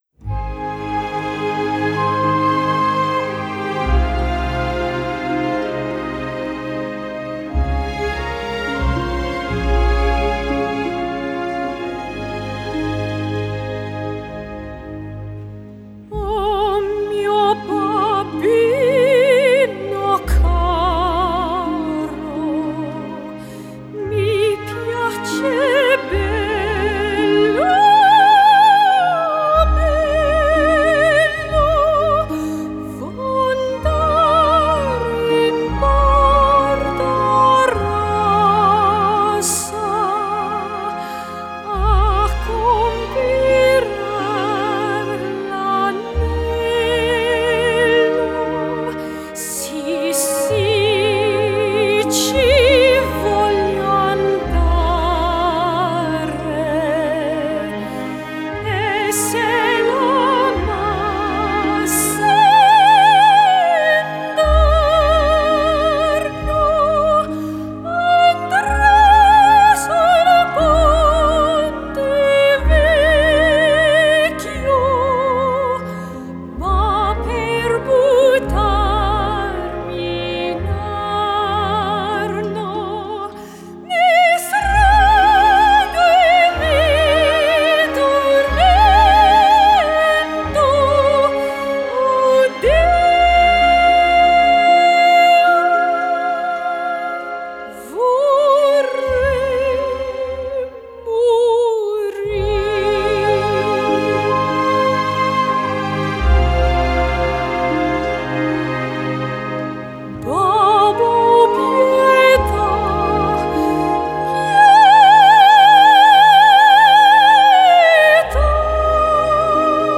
Genre: Classical Crossover, Classical